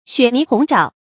注音：ㄒㄩㄝˇ ㄋㄧˊ ㄏㄨㄥˊ ㄓㄠˇ
雪泥鴻爪的讀法